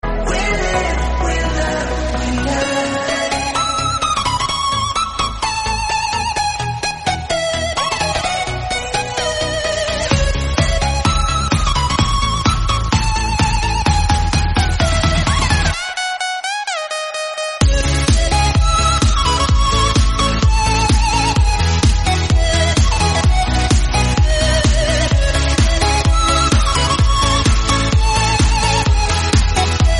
Elektroniczne